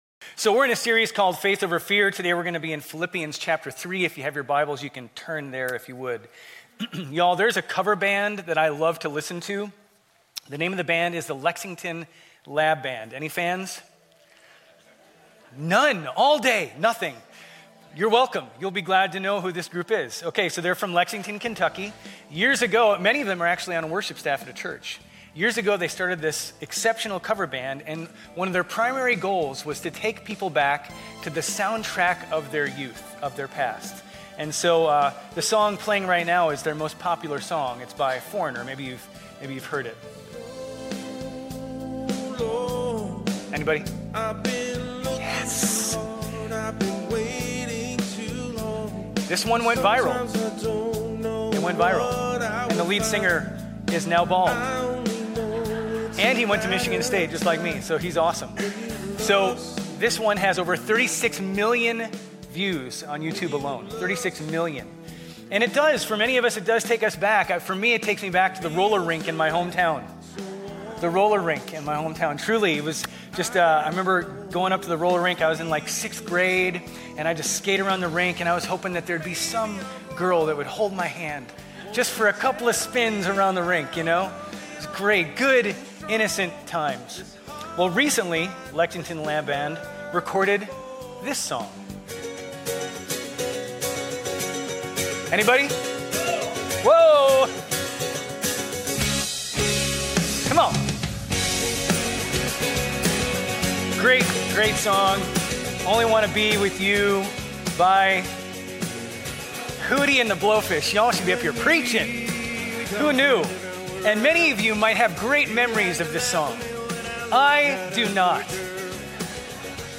Grace Community Church Old Jacksonville Campus Sermons 6_11 Old Jacksonville Campus Jun 02 2025 | 00:30:20 1x 00:00 / 00:30:20 Subscribe Share RSS Feed Share Link Embed